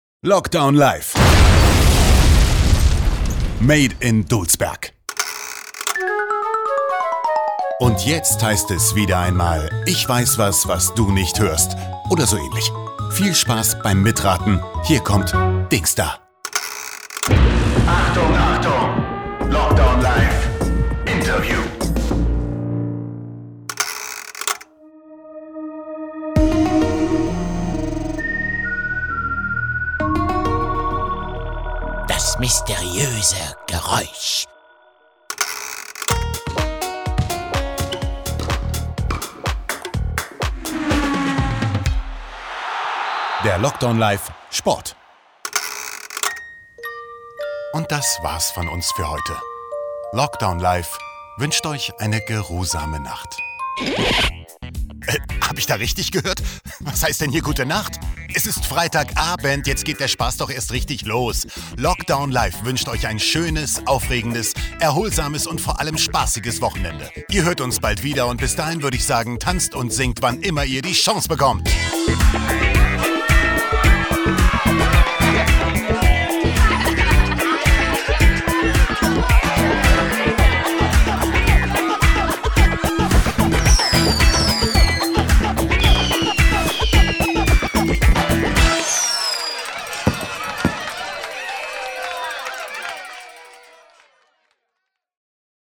Radiojingles: